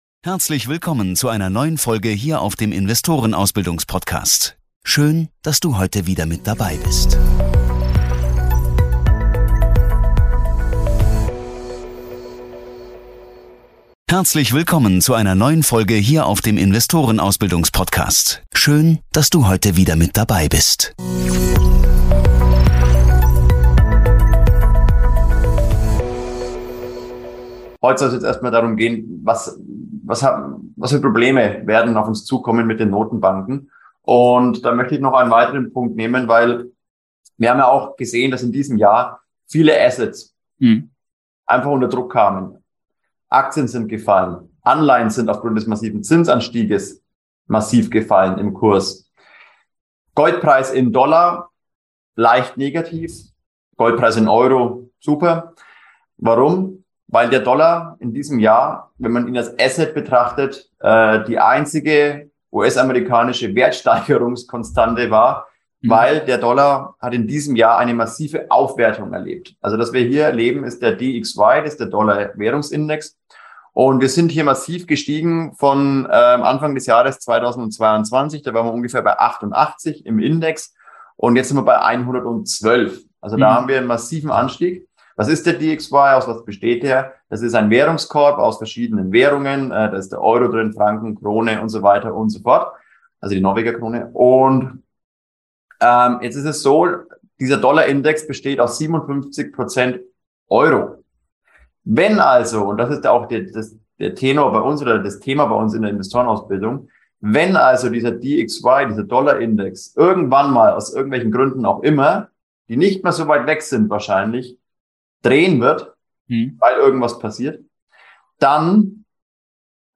Dieses Interview besteht aus 2 Teilen, der erste Teil kam bereits letzten Dienstag, den 01.11.2022. Im 2.